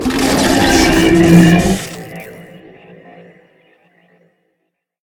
bighurt1.ogg